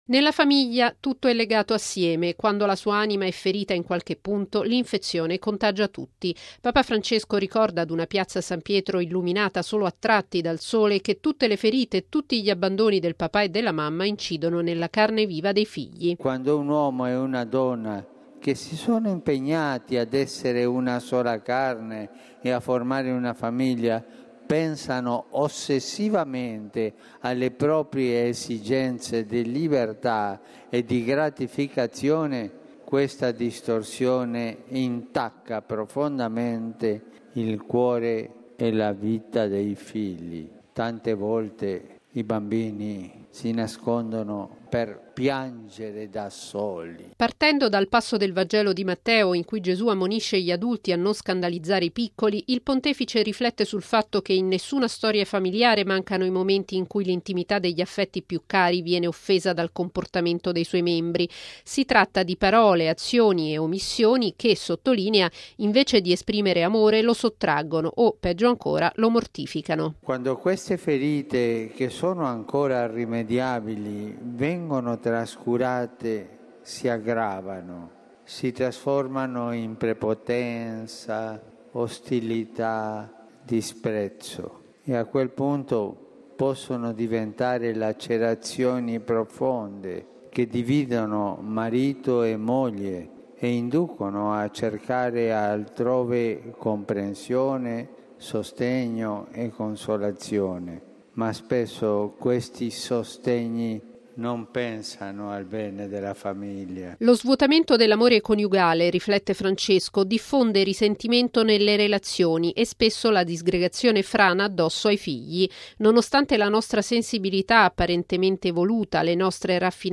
Lo ha detto Papa Francesco all’udienza generale in Piazza San Pietro riflettendo “sulle ferite che si aprono” all’interno della convivenza familiare, quando - ha cioè spiegato - nella famiglia “ci si fa del male”.